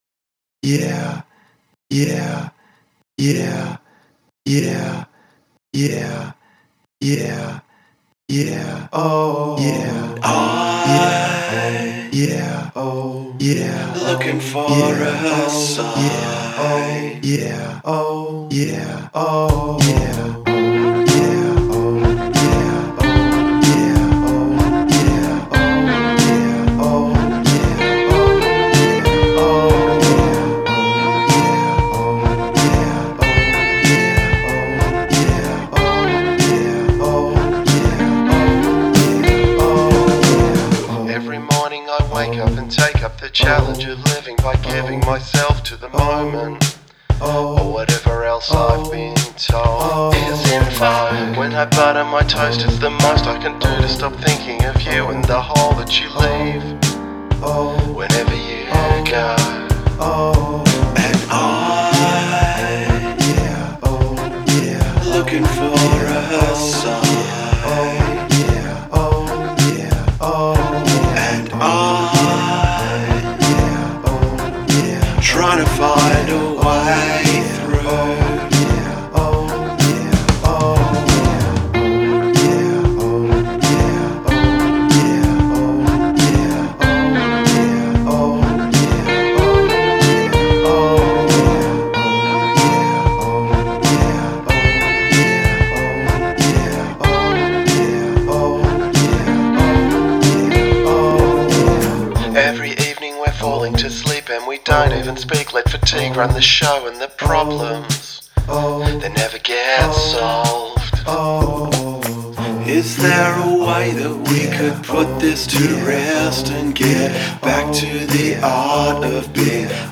and also in the 2nd guitar harmony - Bb G F D / D F G Bb